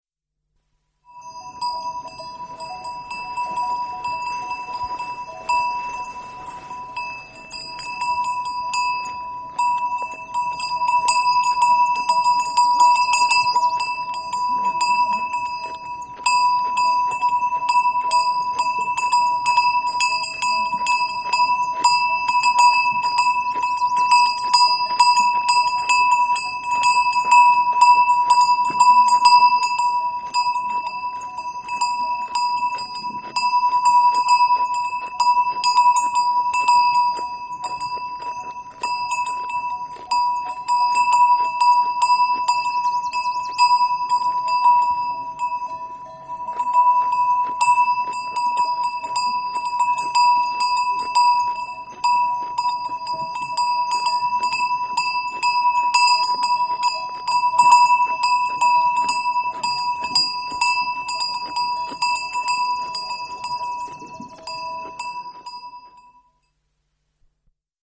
Звон болтающегося колокольчика на шее домашнего яка